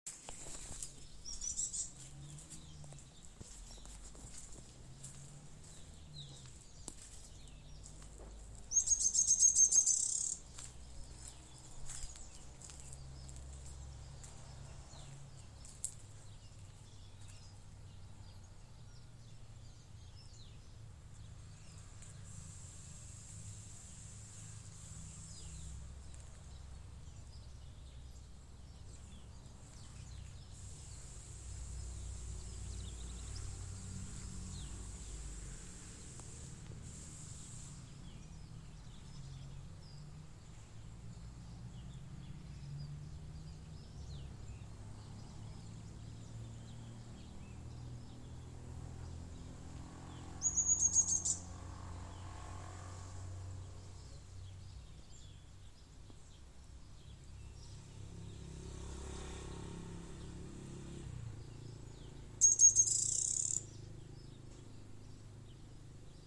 Tufted Tit-Spinetail (Leptasthenura platensis)
Province / Department: Catamarca
Condition: Wild
Certainty: Photographed, Recorded vocal